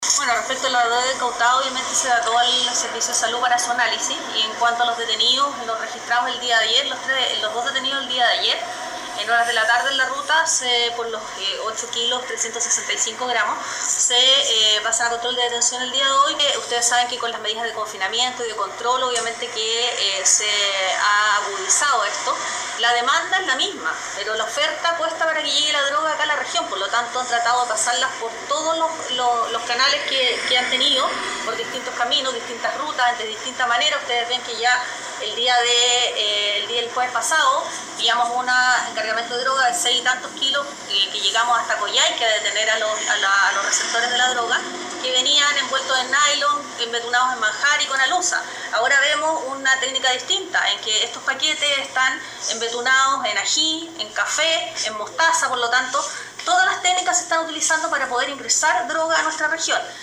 Sobre esta operación antidroga, la fiscal de Osorno María Angélica de Miguel, resaltó el reforzamiento de los controles y de la inteligencia policial, en momentos en que los traficantes apelan a distintas estrategias para introducir esta carga ilegal.
15-FISCAL-MARIA-ANGELICA-DE-MIGUEL.mp3